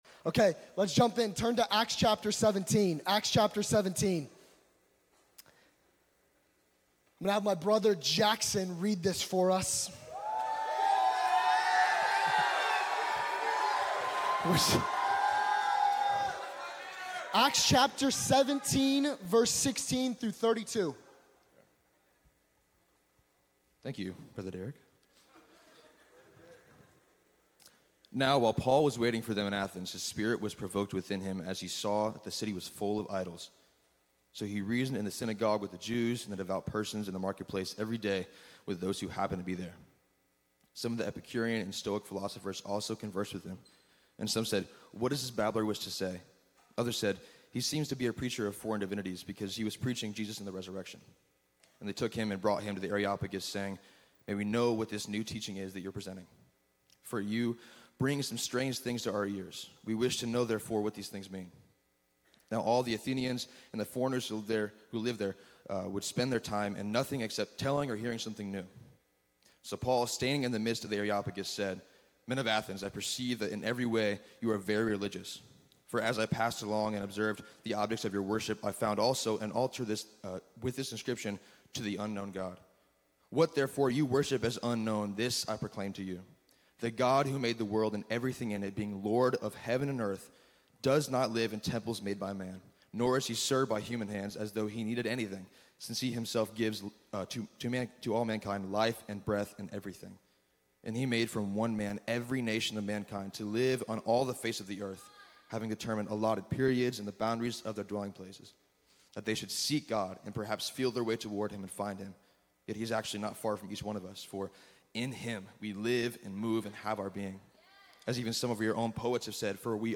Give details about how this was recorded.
at Circuit Riders Monday Nights